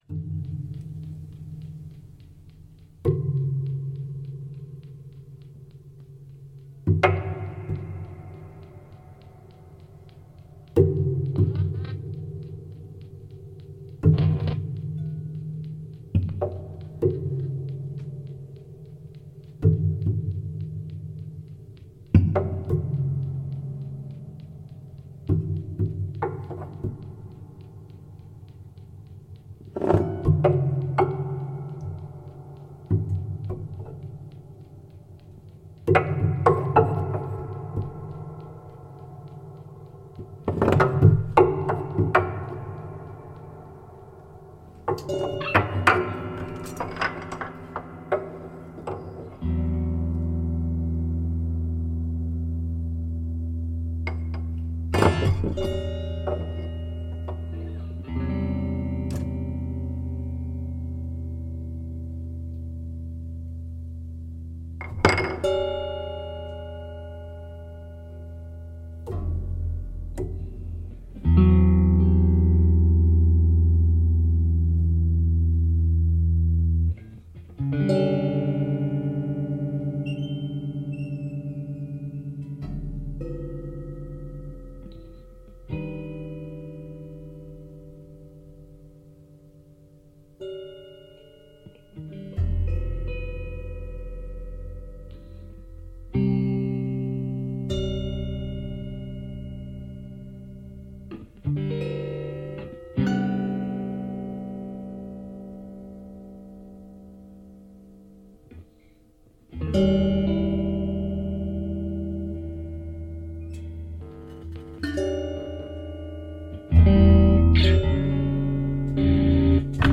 piano
electric guitar